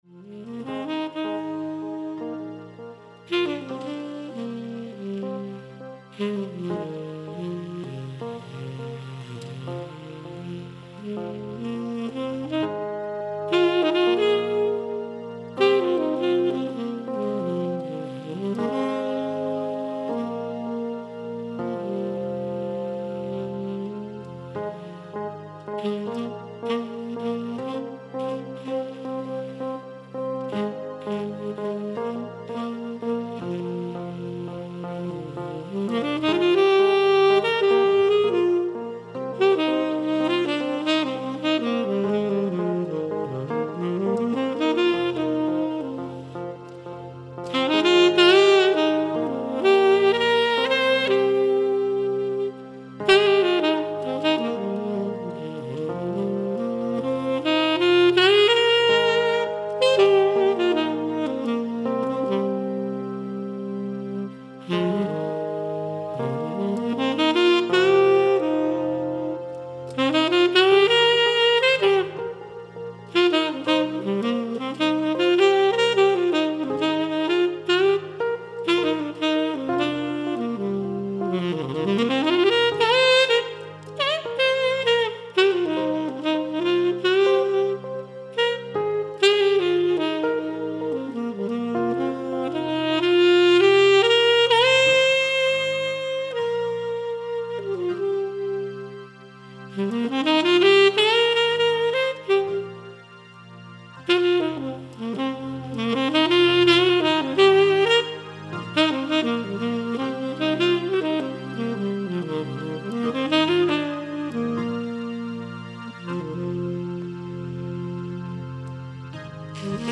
Jazz - Organ with Sax